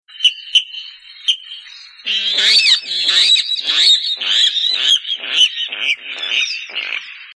Râle d'eau, rallus aquaticus
rale.mp3